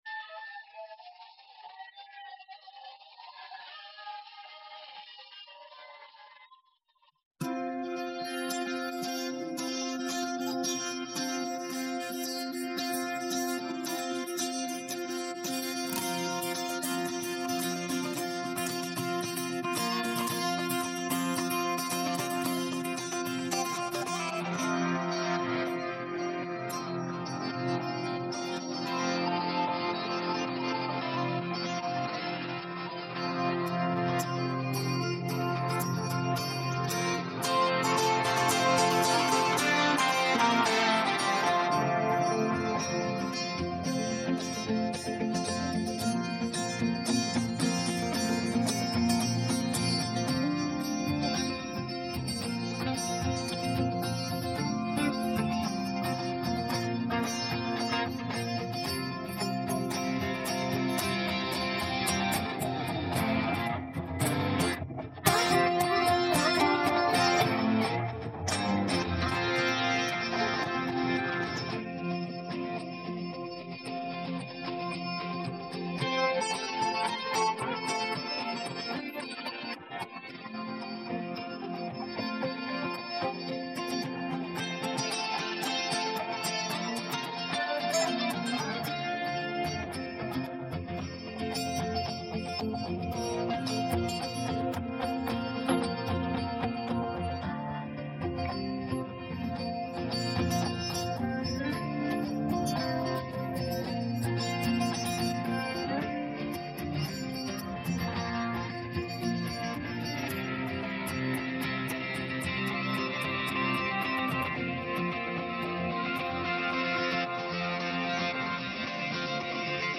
soulful guitar track